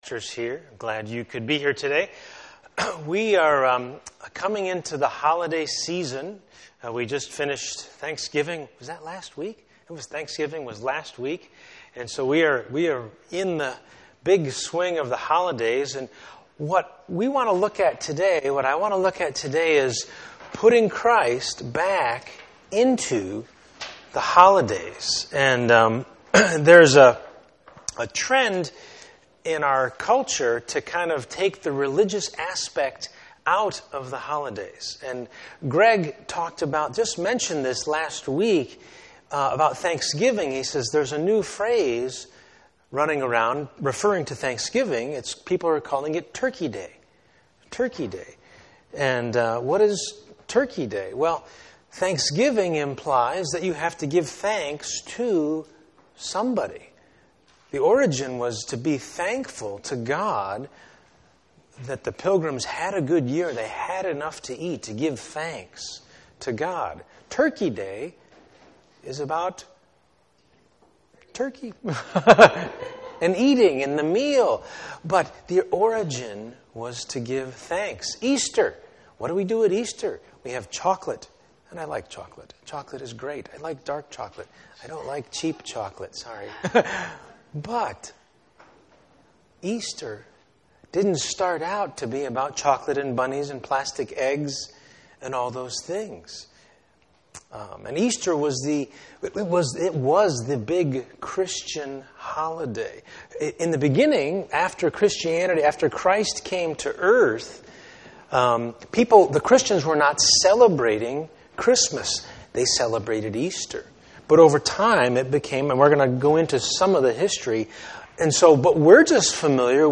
Christmas Service Type: Sunday Morning %todo_render% « In Everything